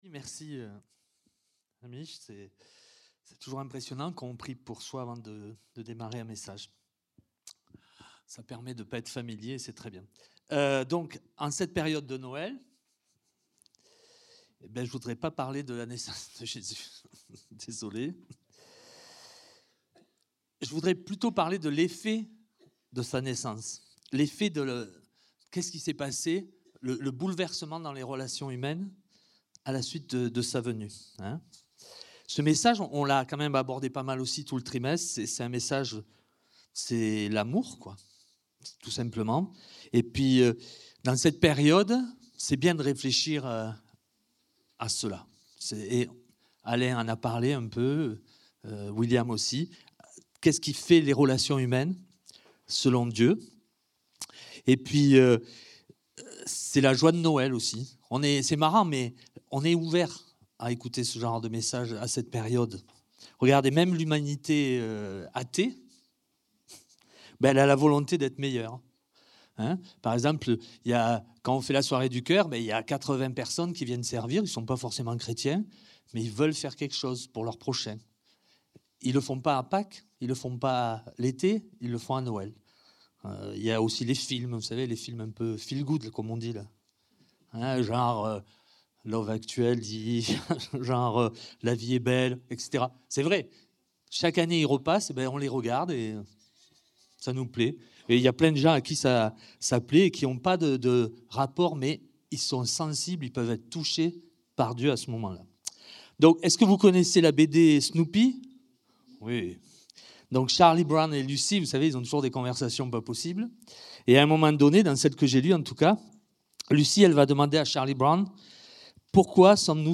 Culte du dimanche 21 décembre 2025, prédication